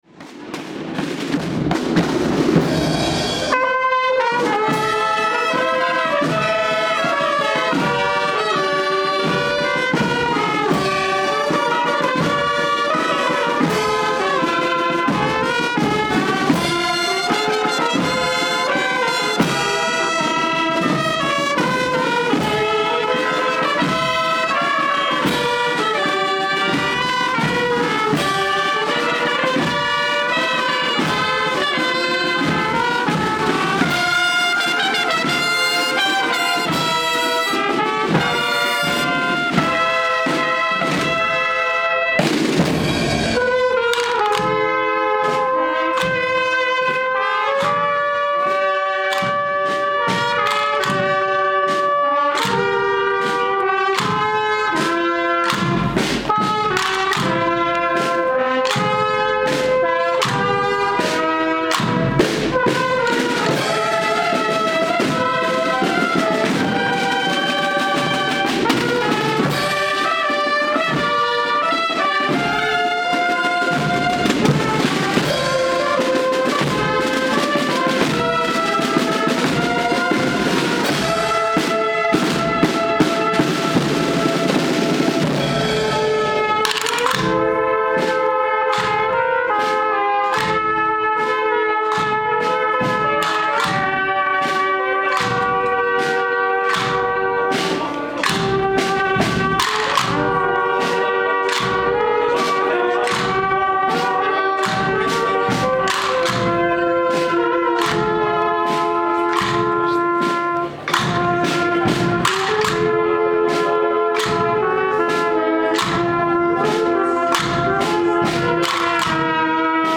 Procesión Año de la Fe
A las 19:00 se celebró una Misa Solemne a Ntr. Sra. de la Fe en la Iglesia de Santiago de Totana y a continuación tuvo lugar una procesión.